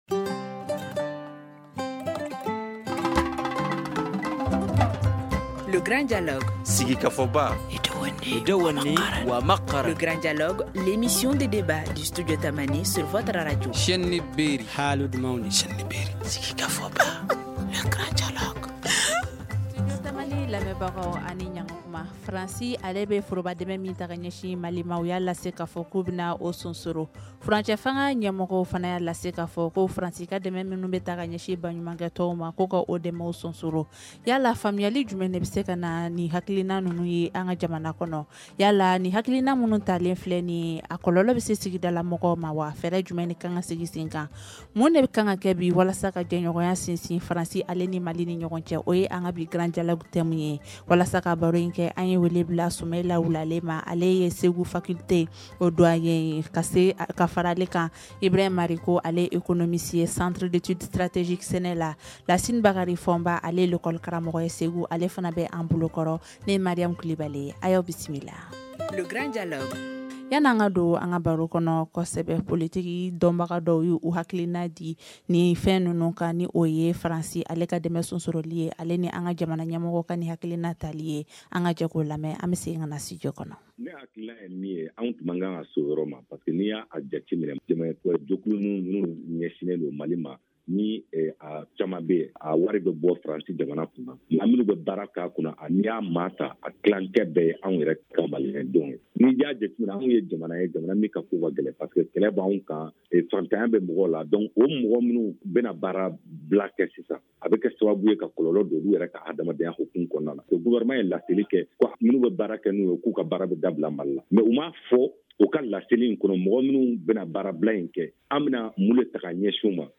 Comment améliorer les relations entre les deux pays ? Le Grand Dialogue de Studio Tamani s’intéresse à ces sujets, depuis Ségou.